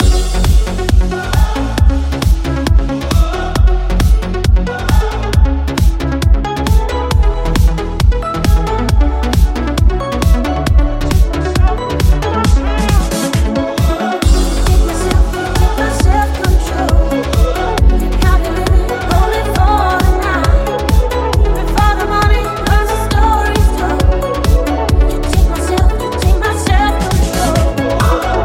deep house
Genere: house, deep house, remix